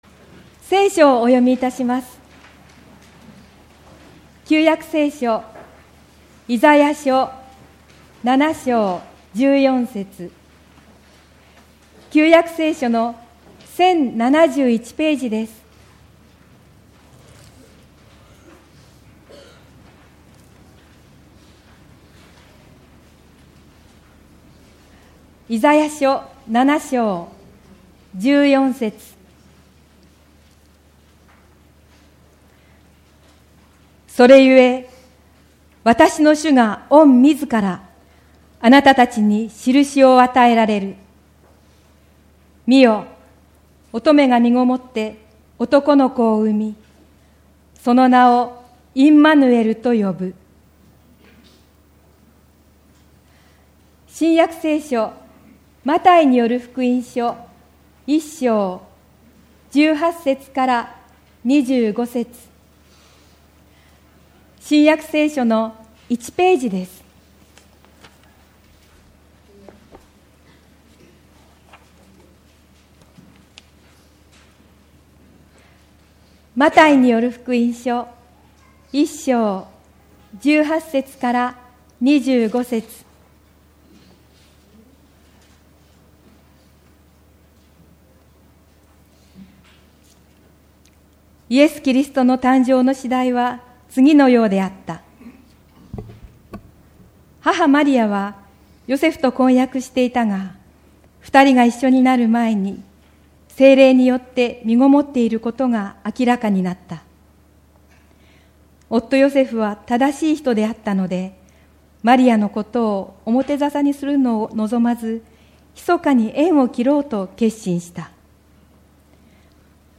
クリスマス礼拝